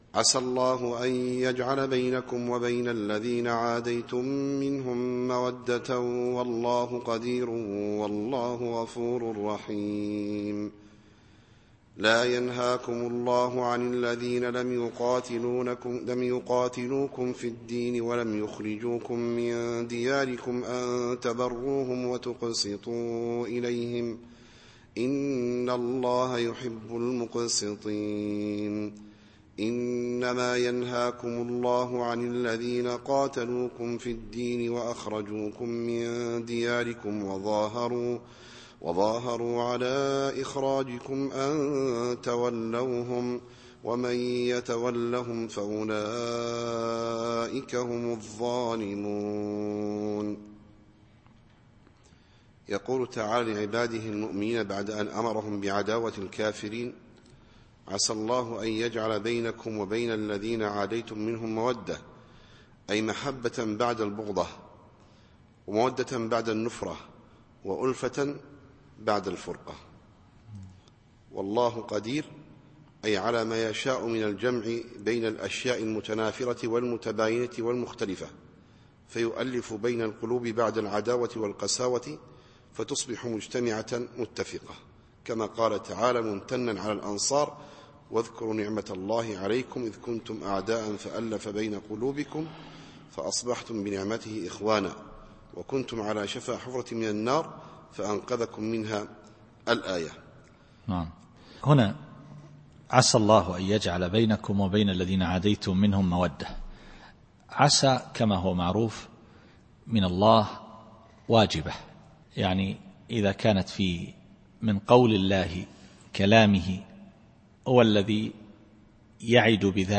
التفسير الصوتي [الممتحنة / 7]